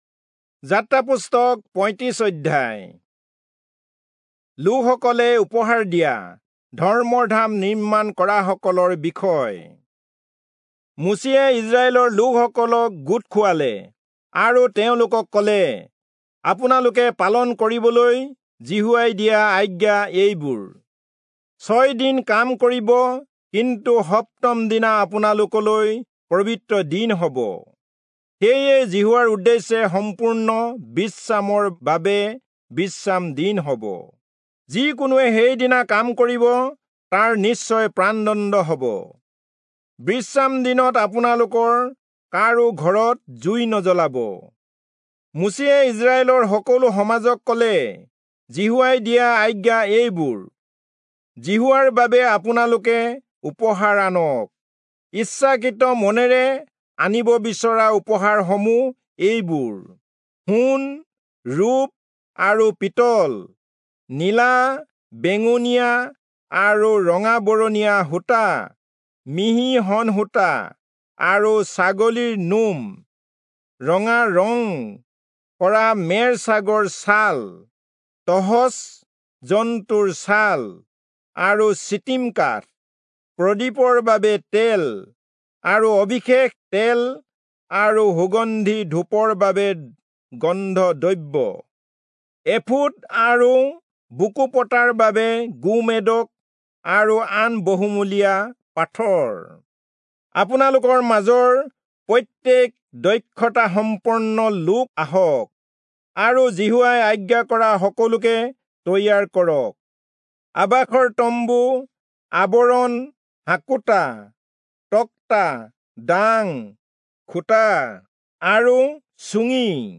Assamese Audio Bible - Exodus 7 in Ocvhi bible version